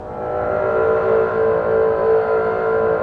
Index of /90_sSampleCDs/Roland L-CD702/VOL-1/STR_Cbs FX/STR_Cbs Sul Pont